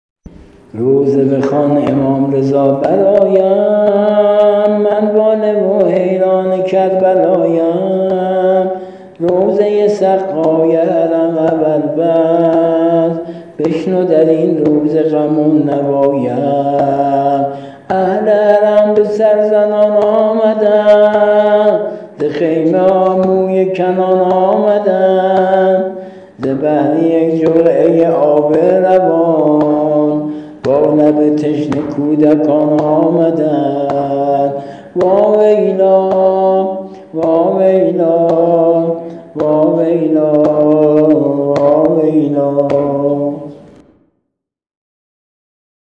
◾زمزمه و نوحه سینه زنی